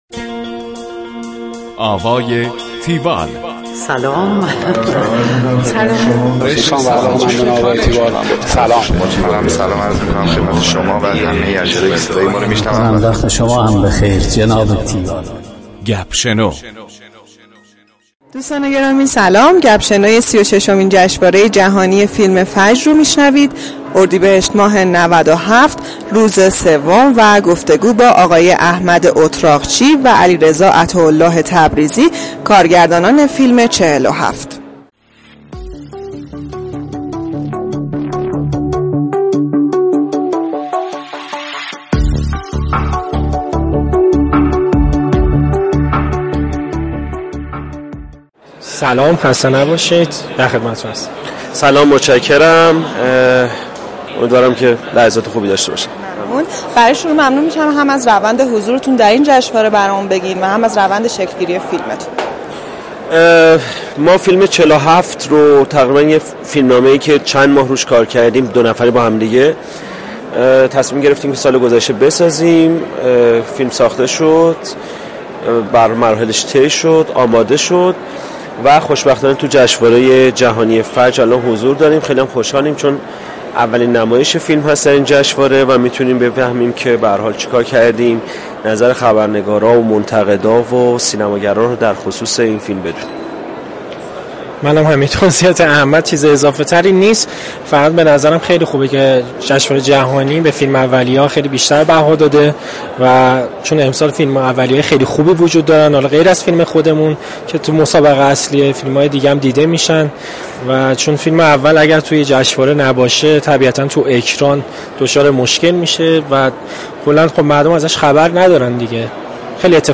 گفتگوی تیوال
tiwall-interview-47.mp3